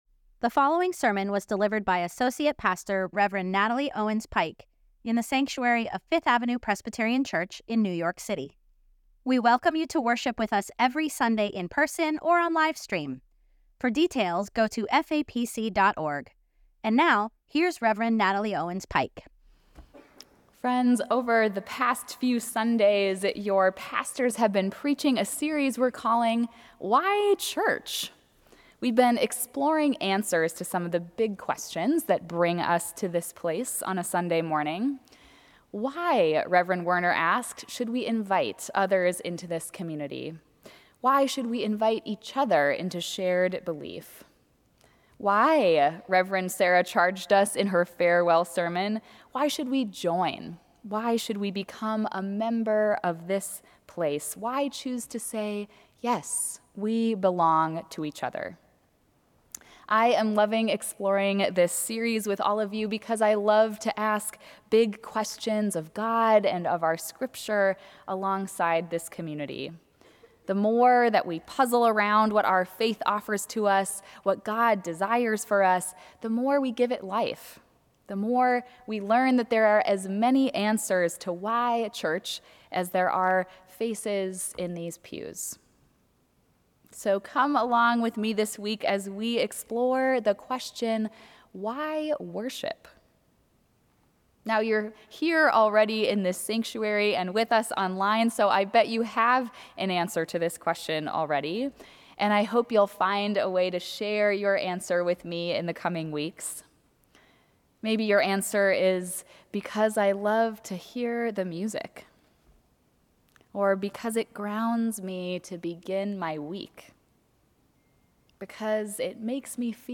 Eleventh Sunday after Pentecost | Sermons at Fifth Avenue Presbyterian Church
Sermon: “Why Worship? ” Scripture: Matthew 17:1-8 Download sermon audio Order of Worship S ix days later, Jesus took with him Peter and James and his brother John and led them up a high mountain, by themselves.